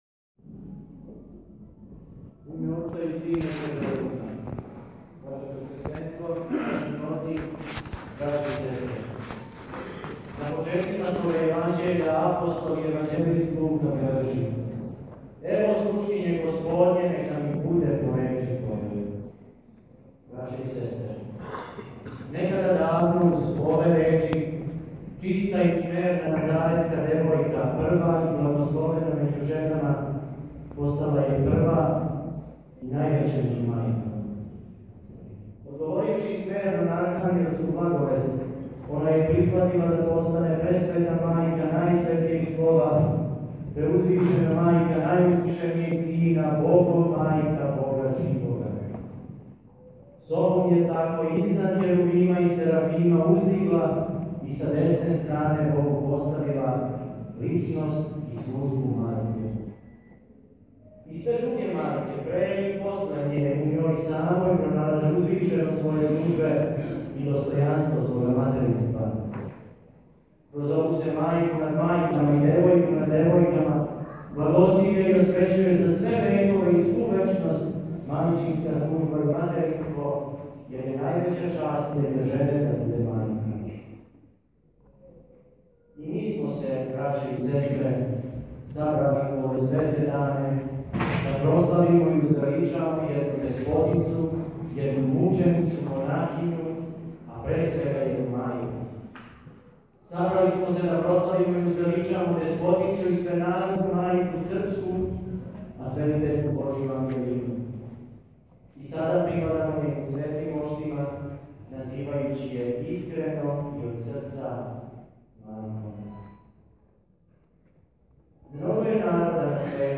Беседa у Саборном храму у Шиду, поред руке Преподобне мајке Ангелине: